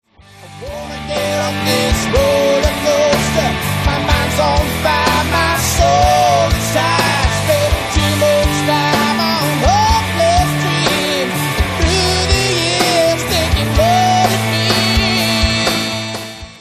Vocals & Percussion
Guitar & Vocals
Guitar & Synthesizer
Fretless Bass
Drums